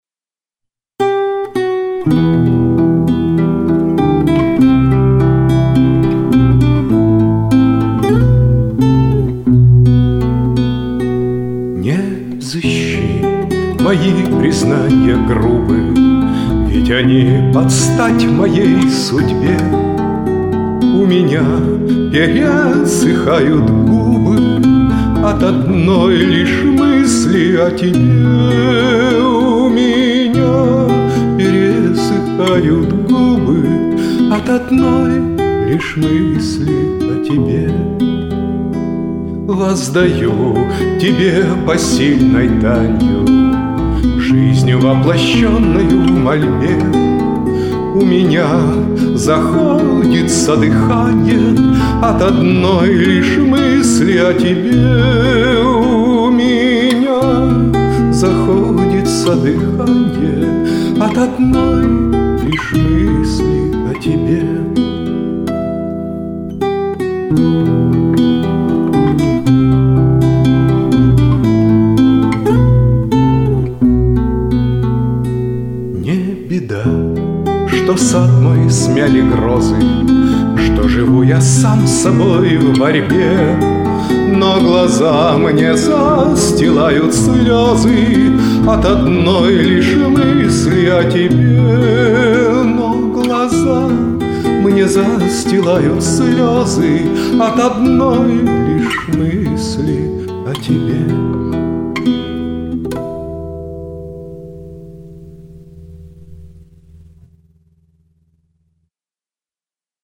Романс на эти стихи в мужском исполнении